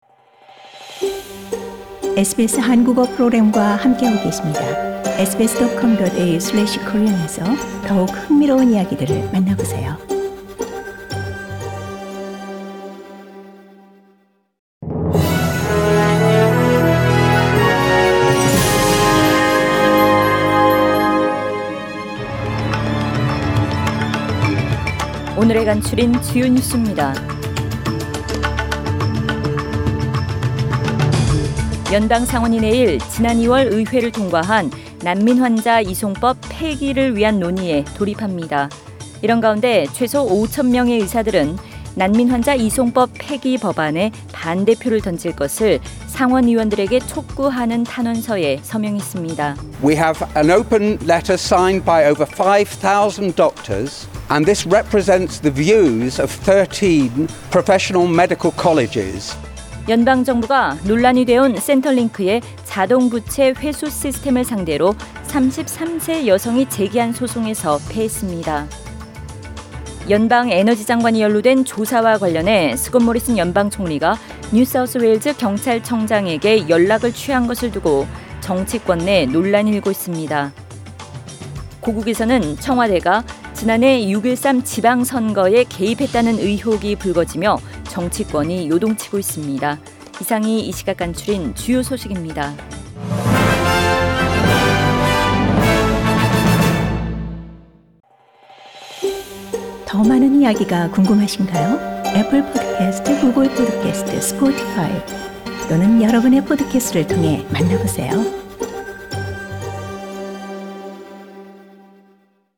Find out Today’s top news stories on SBS Radio Korean.